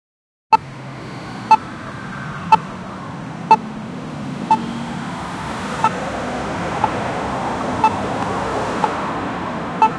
pedestrian-street-sound-dnwq5cpn.wav